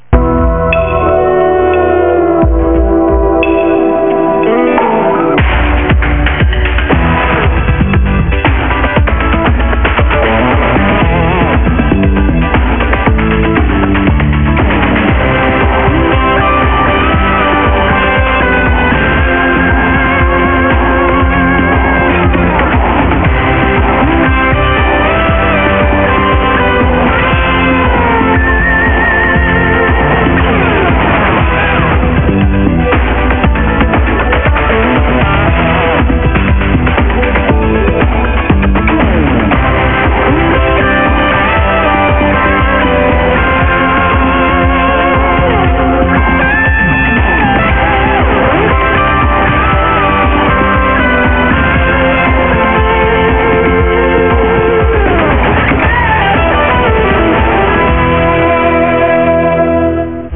Fõcímzene